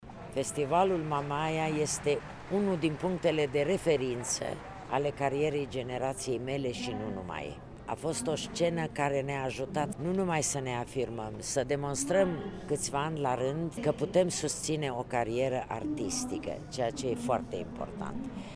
Membru al juriului, Corina Chiriac a vorbit despre importanța festivalului: